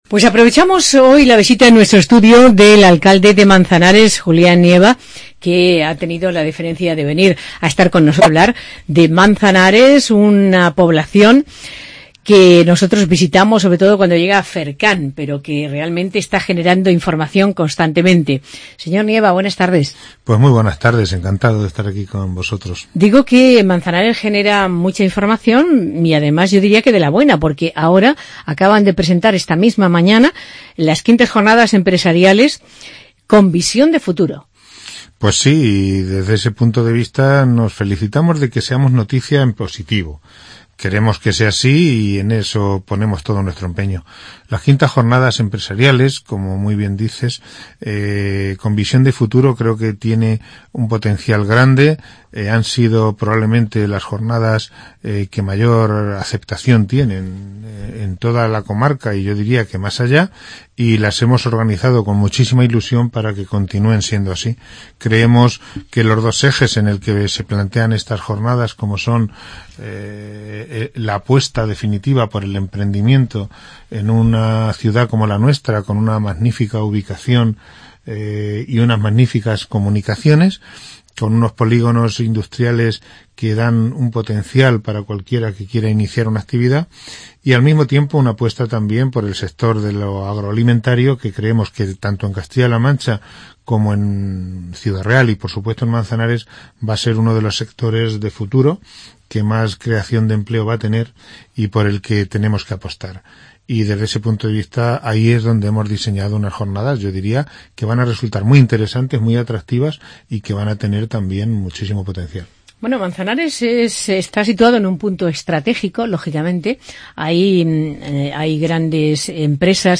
Entrevista Alcalde de Manzanares, Julián Nieva 6-11-15